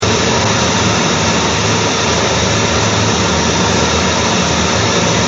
1. In de zendkamer wordt een ruissignaal opgelegd binnen een voldoende breed frequentiegebied;
In een eerste geluiddemo is het signaal aan de zendzijde te horen.
zend-met.jpg (879 bytes) signaal zendzijde